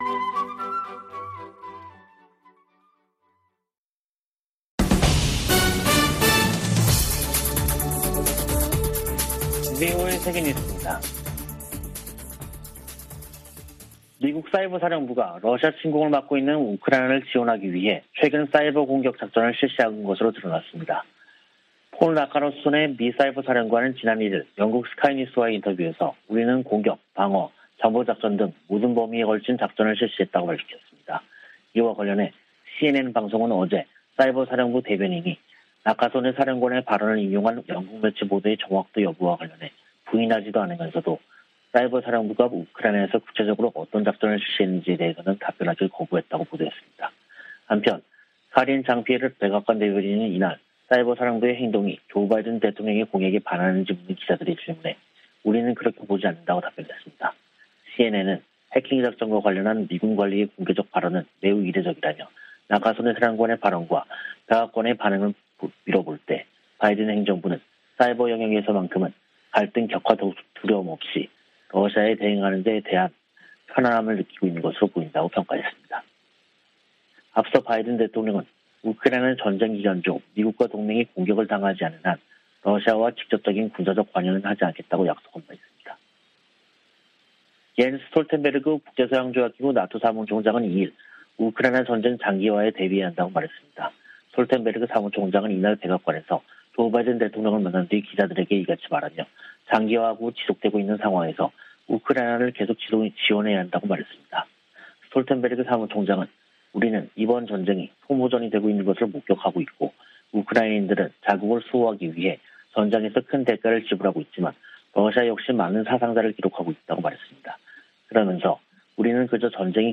VOA 한국어 간판 뉴스 프로그램 '뉴스 투데이', 2022년 6월 3일 3부 방송입니다. 미국과 한국, 일본의 북핵 수석대표들은 북한의 7차 핵실험 준비 동향에 대해 긴밀한 공조를 통한 억제력 강화 등을 경고했습니다. 미 국무부는 2021년도 종교자유보고서에서 북한의 종교 탄압이 심각히 우려되고 있다고 지적했습니다.